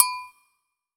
HLWAGOGO.wav